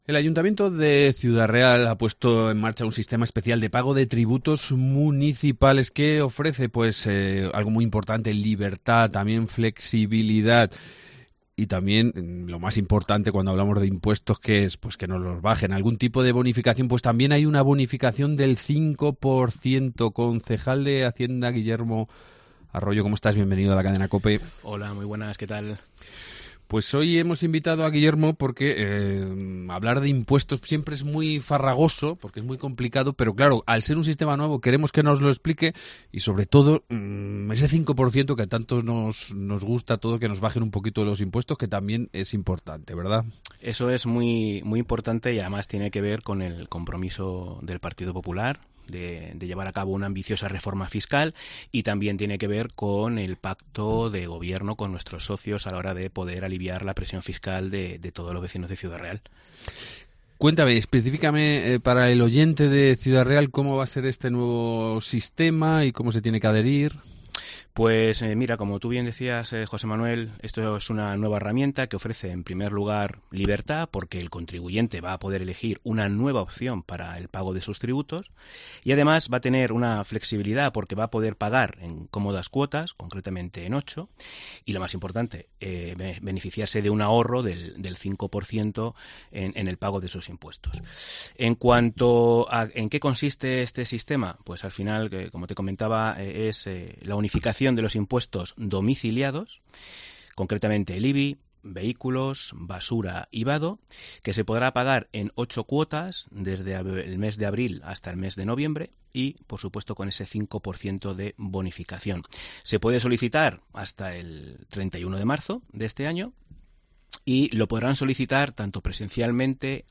Entrevista con Guillermo Arroyo, concejal de Hacienda